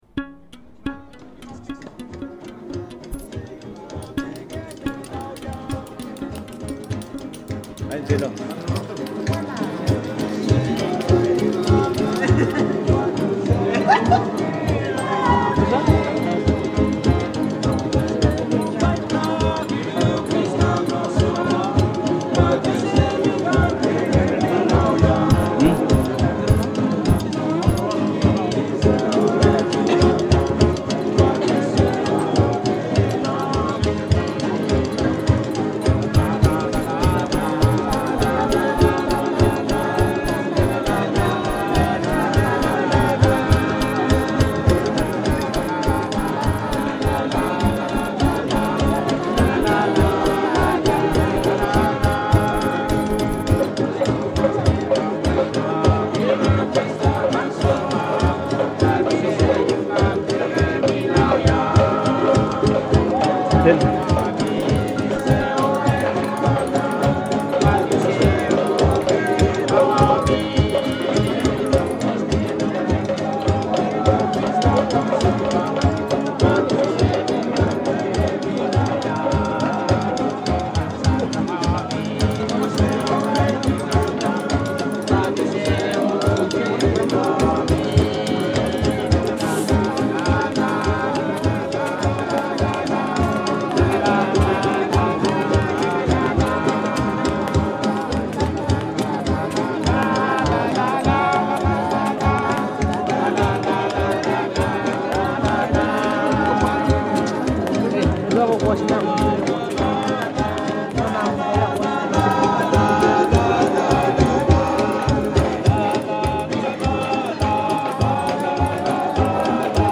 When I first heard this field recording, I wanted to join in with the band on the beach. With every subsequent listen, I always find myself tapping or nodding along with the beat and wanting to dance. I decided to pursue this idea of jamming with the band across time, mixing in both my own high and low-tech approaches to creating sounds.
This piece uses the first 2:20 of the 5:51 field recording with no added effects as the backbone, this sample plays throughout the duration of the track. Within the entire field recording, this section sounds like a specific song that starts and ends.